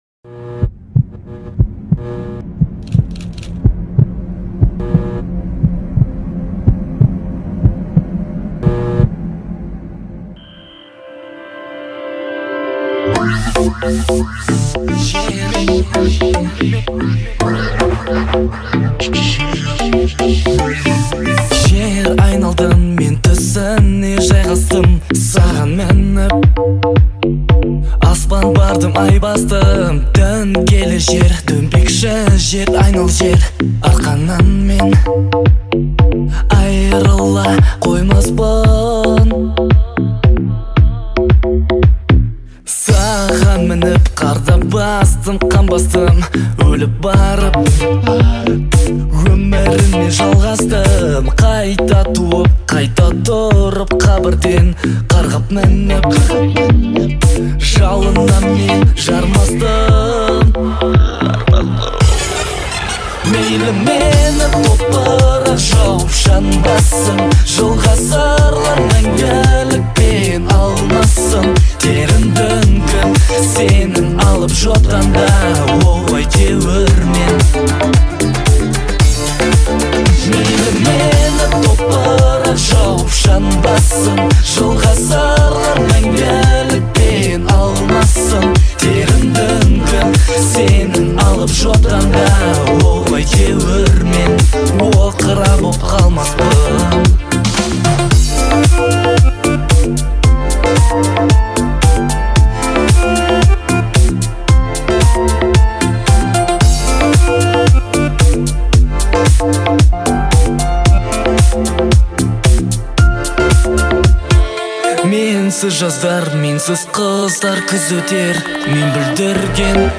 что делает трек запоминающимся и мелодичным.